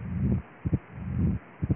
B1M com sopro, holosistólico, que encobre a B2, apical, alto, soprante, áspera, musical, com irradiação para a axila.
B1T com estalido